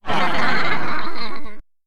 young girls giggling and laughing
children female fun funny giggle giggling girls happy sound effect free sound royalty free Funny